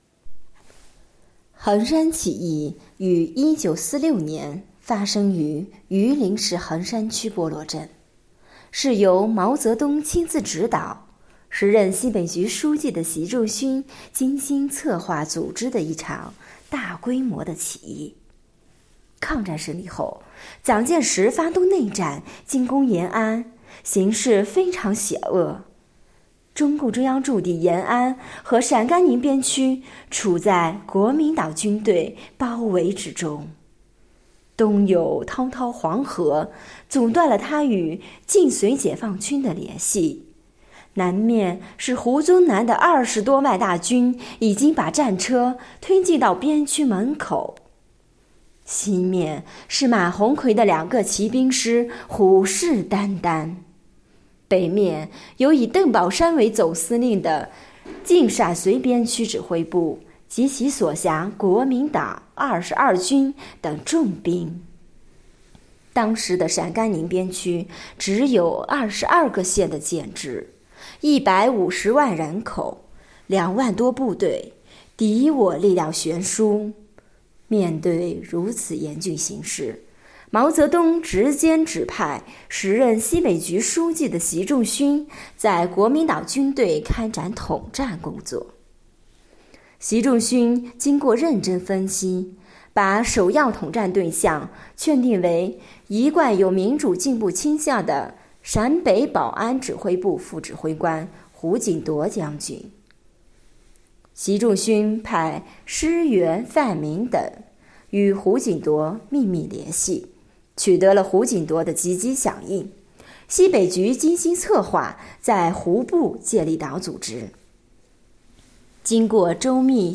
【红色档案诵读展播】横山起义